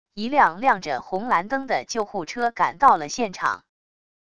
一辆亮着红蓝灯的救护车赶到了现场wav音频